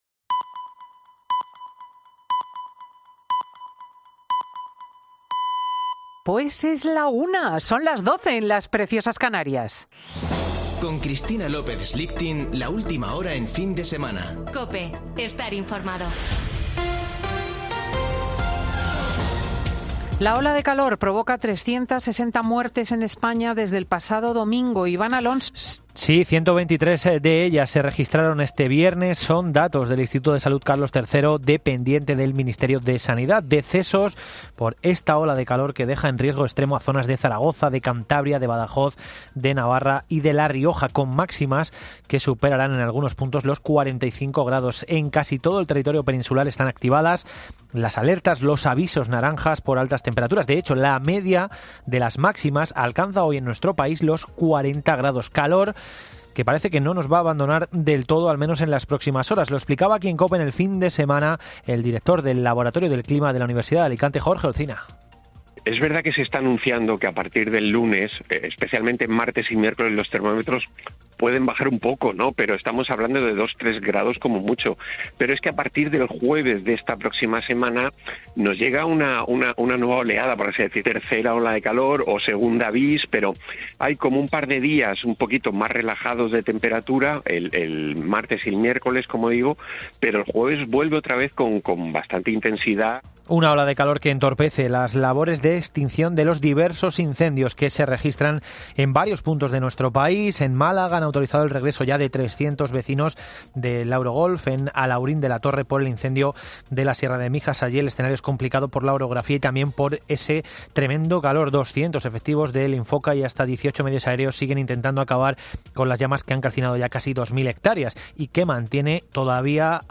Boletín de noticias de COPE del 16 de julio de 2022 a las 13:00 horas